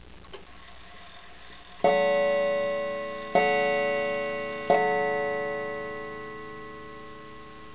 Seth Thomas "Sentinel No 4" Clock